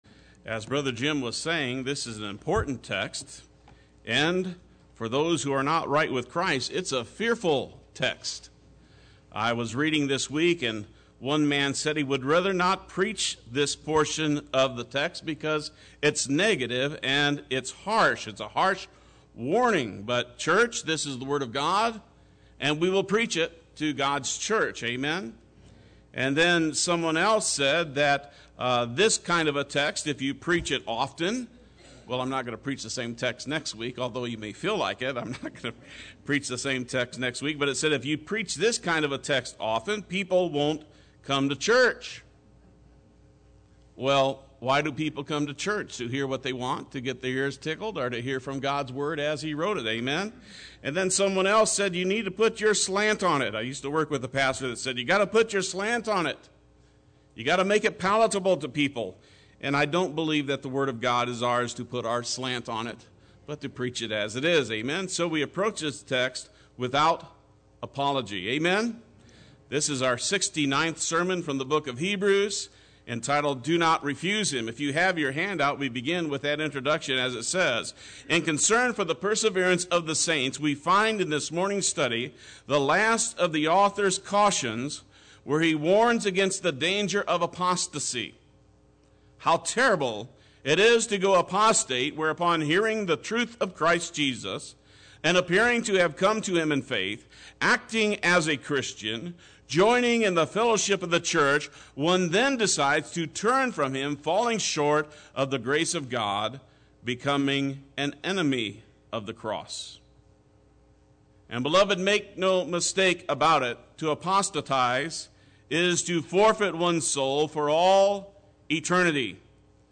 Play Sermon Get HCF Teaching Automatically.
“Do Not Refuse Him” Sunday Worship